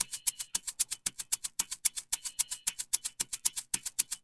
LQT SHAKER.wav